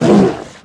Minecraft Version Minecraft Version 1.21.4 Latest Release | Latest Snapshot 1.21.4 / assets / minecraft / sounds / mob / polarbear / hurt1.ogg Compare With Compare With Latest Release | Latest Snapshot
hurt1.ogg